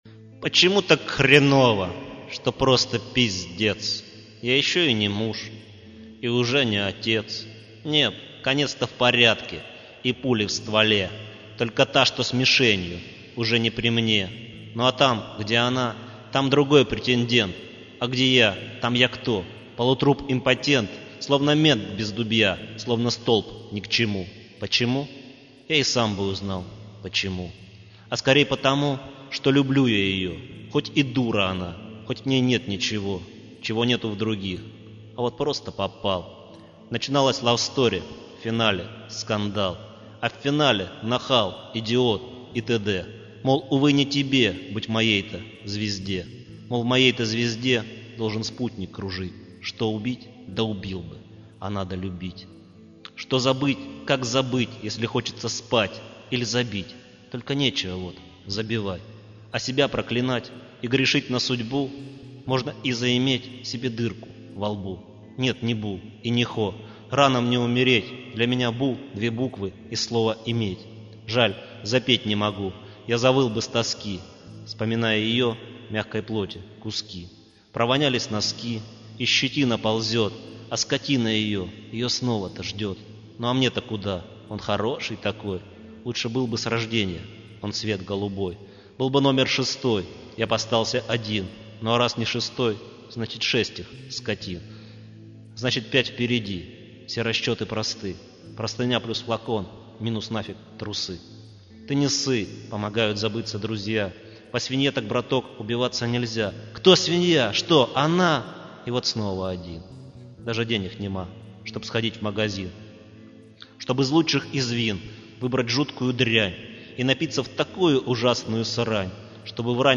13.Мужская злоба (стих)